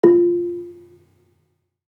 Gambang-F3-f.wav